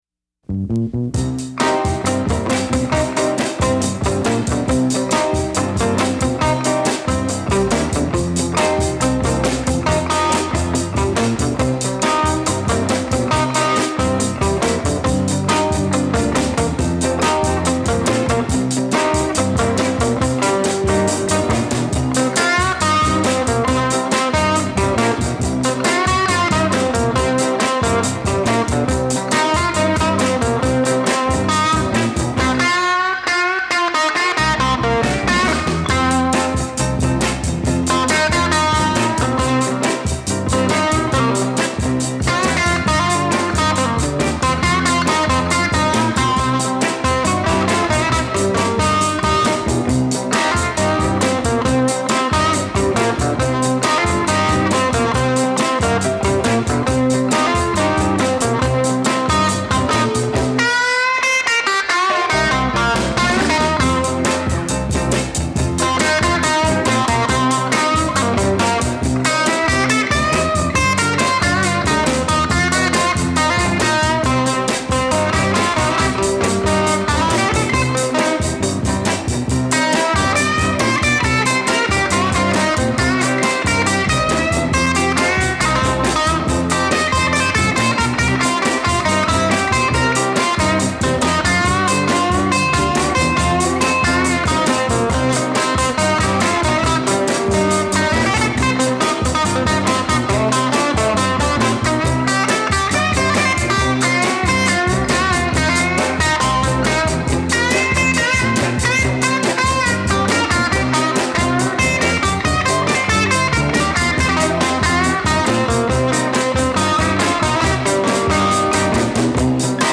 Blues Music - Six Electric Guitar Recordings
Guitar Blues